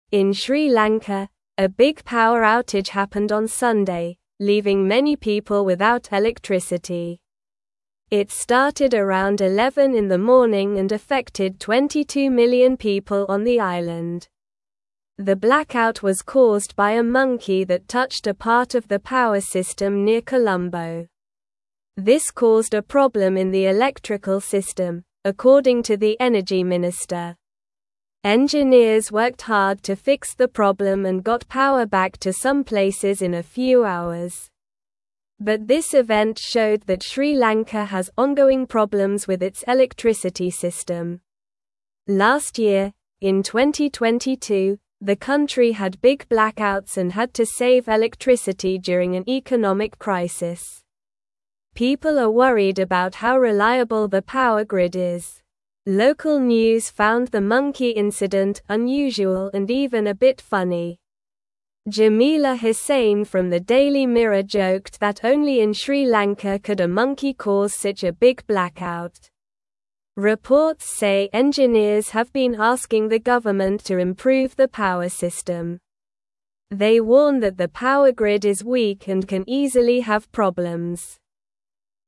Slow
English-Newsroom-Lower-Intermediate-SLOW-Reading-Monkey-Turns-Off-Lights-in-Sri-Lanka-for-Everyone.mp3